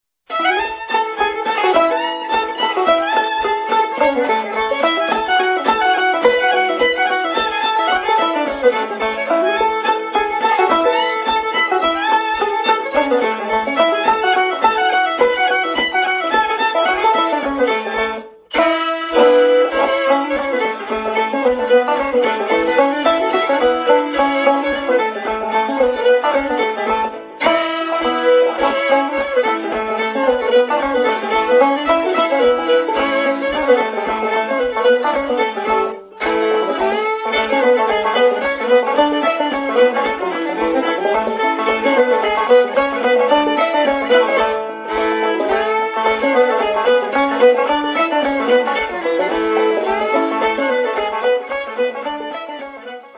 Track 2 is an instrumental
fiddle
banjo